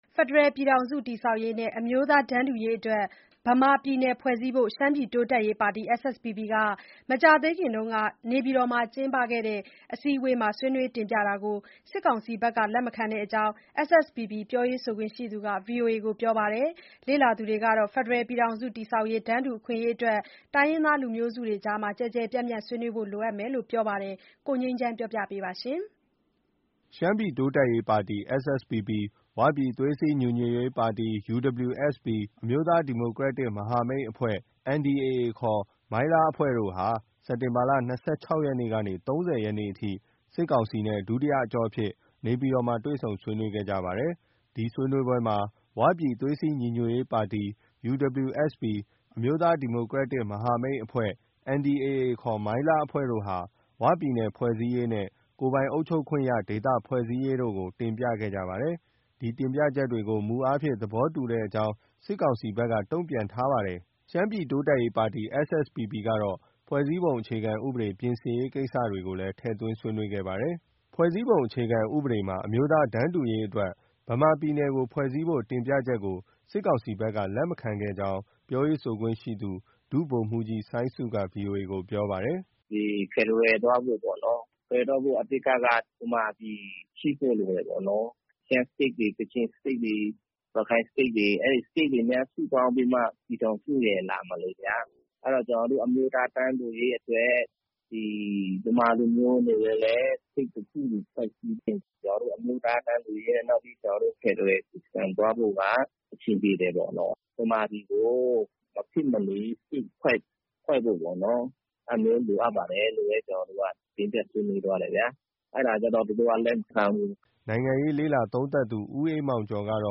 ရနျကုနျကသတငျးပေးပို့ခကြျကို